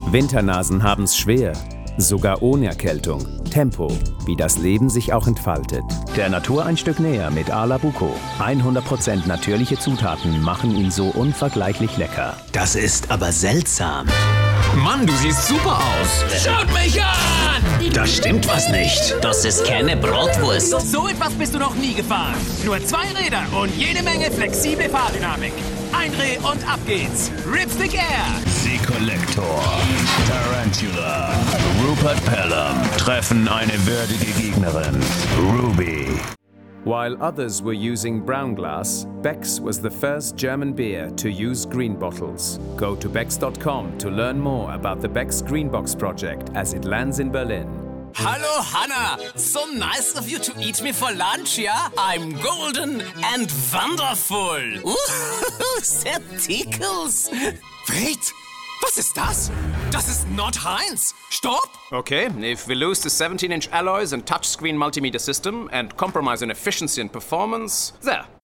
Narration Reel
Playing age: 30 - 40sNative Accent: French, German, International, SpanishOther Accents: French, German, Italian, Russian, Spanish
He has a versatile voice ranging from a reassuring warm baritone to youthful high-energy delivery and has an exceptional ear for accents and character voices.
He has a professionally equipped home studio for remote recording.